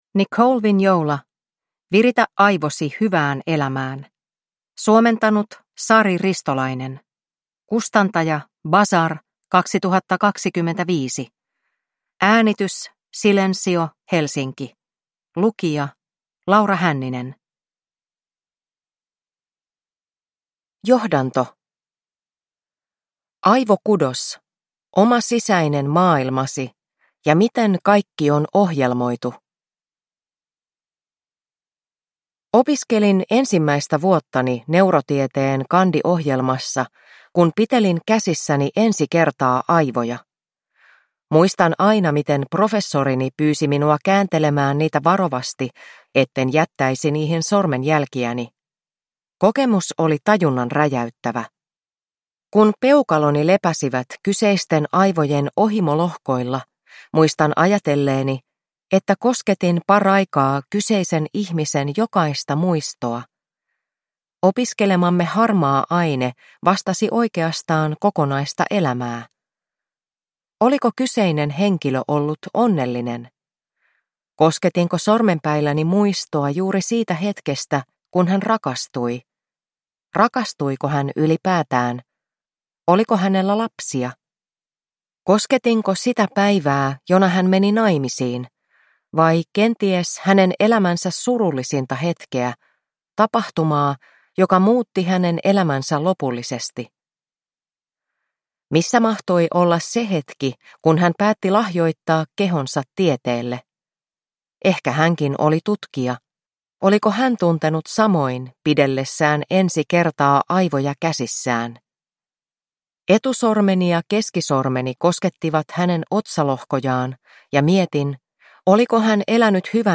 Viritä aivosi hyvään elämään – Ljudbok